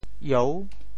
燿 部首拼音 部首 火 总笔划 18 部外笔划 14 普通话 yào 潮州发音 潮州 ieu6 文 中文解释 耀 <动> (形声。
ieu6.mp3